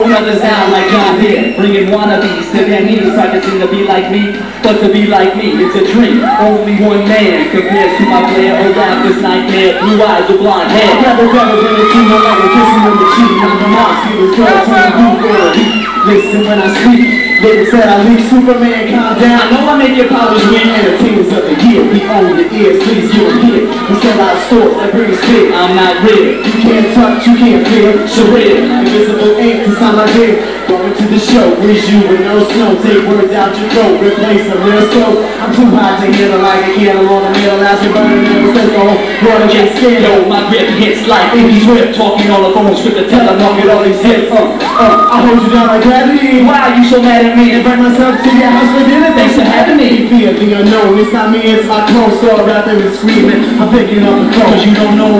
Live from Schalmont high 00